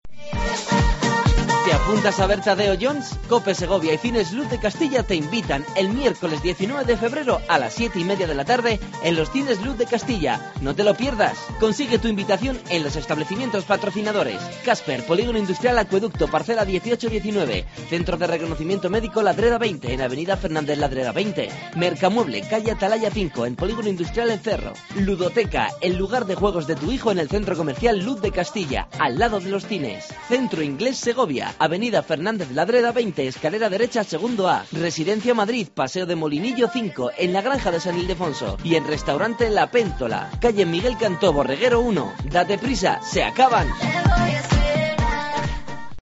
ANUNCIO PASE ESPECIAL DE LA PELICULA TADEO JONES